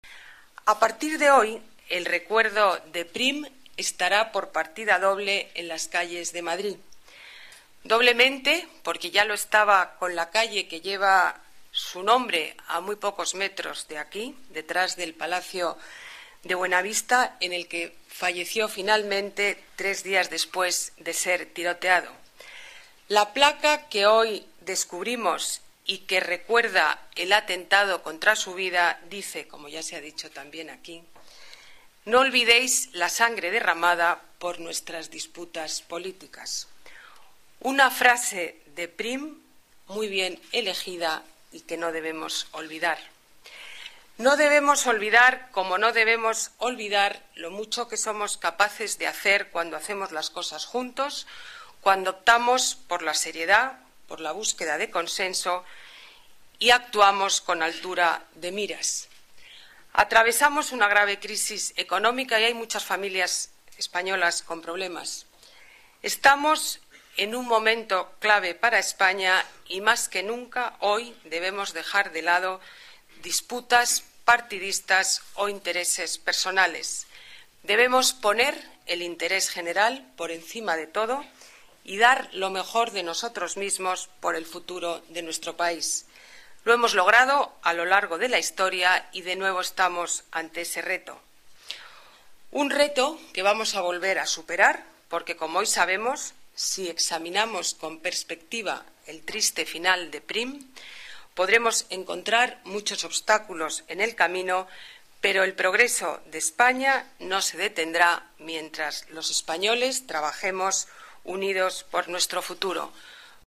Nueva ventana:Declaraciones alcaldesa, Ana Botella: homenaje al general Prim